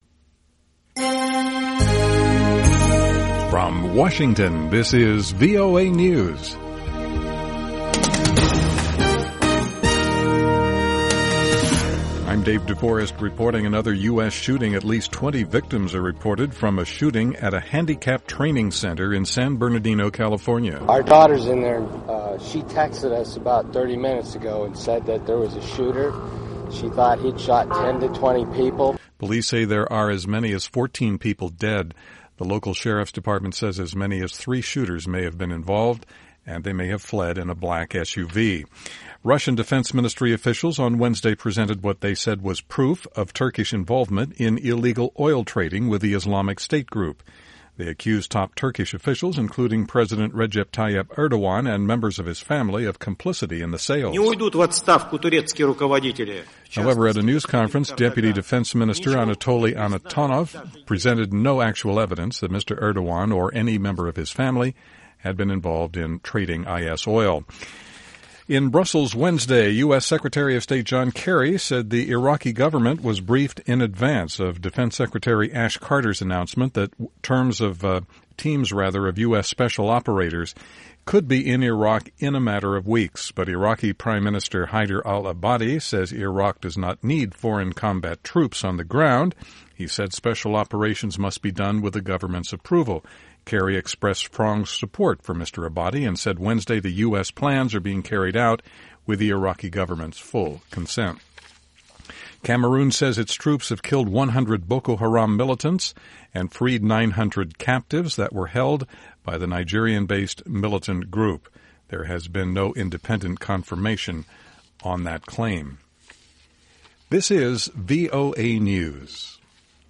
VOA English Newscast 2200 UTC: December 2, 2015